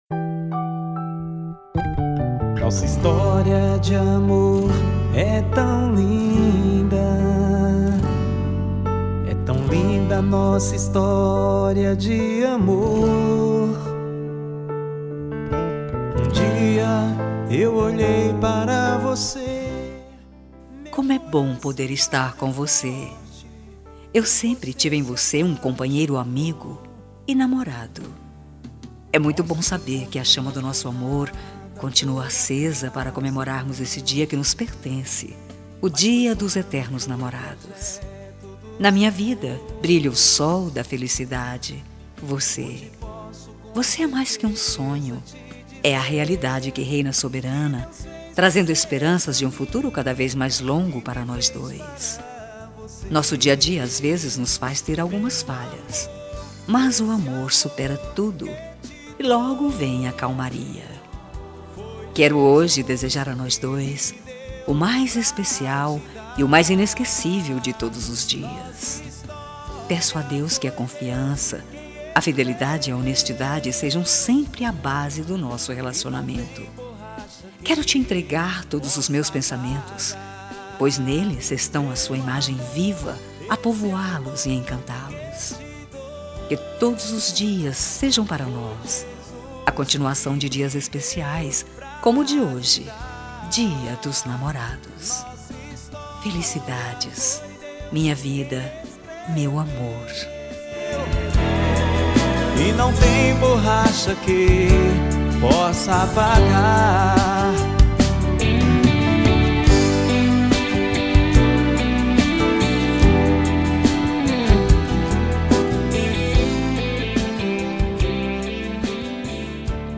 Voz Feminina
Código: 111114 – Música: Nacional Evangélica – Artista: Desconhecido